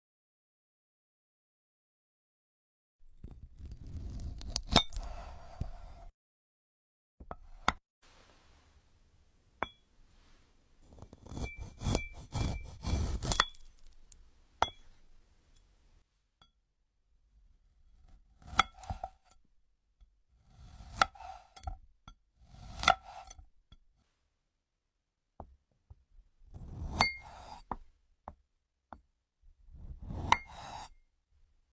ASMR Fruit CUTTING Rank 🌟 sound effects free download